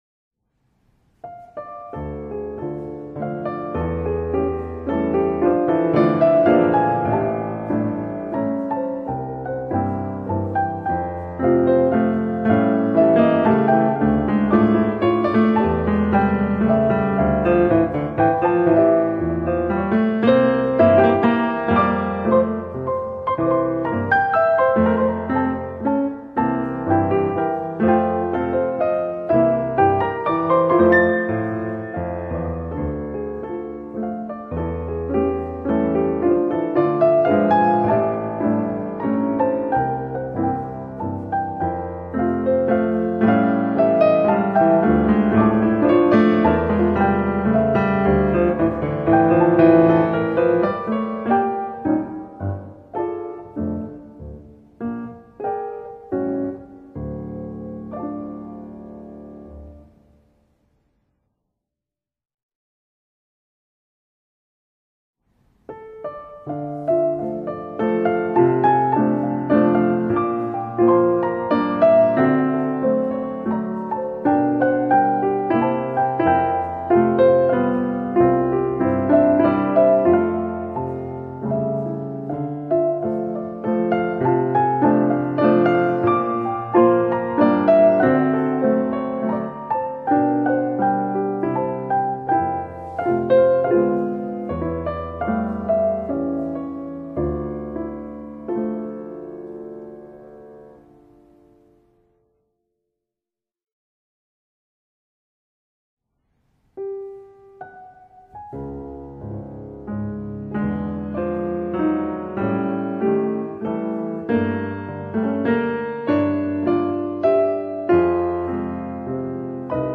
/1 piano, 4 mains
[4 mains]